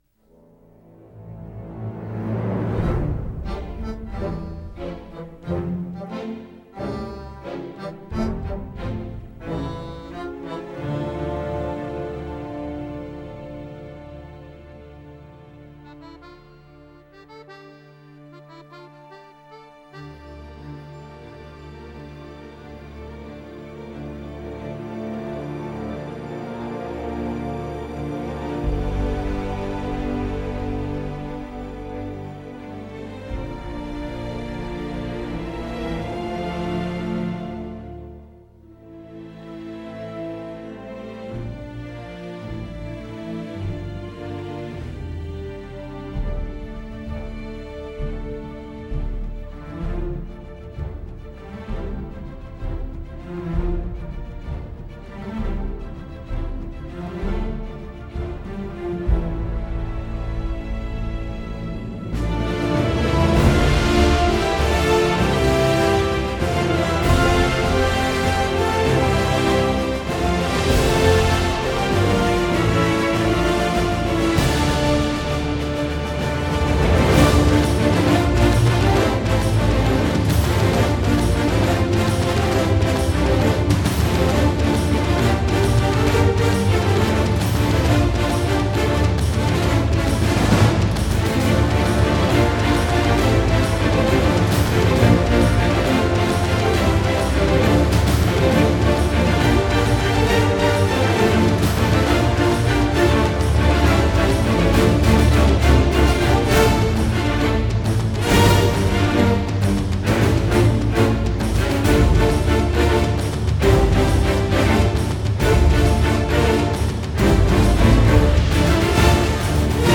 Тільки для тих хто любить класику.